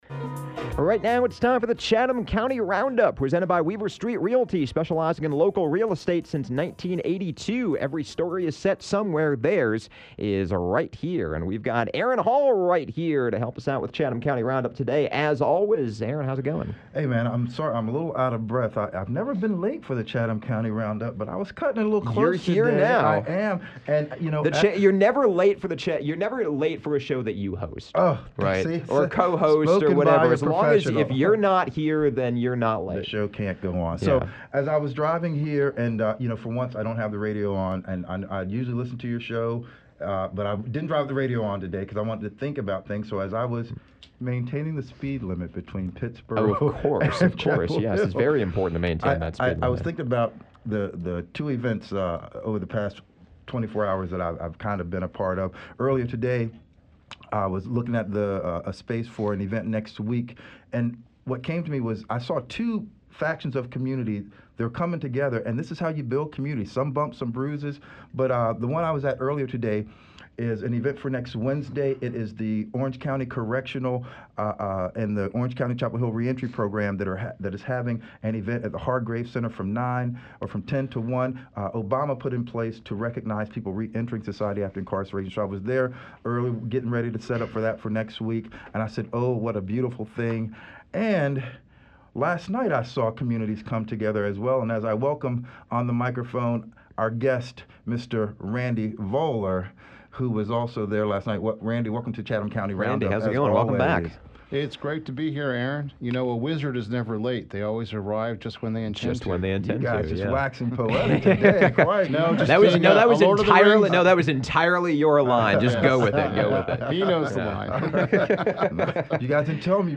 a discussion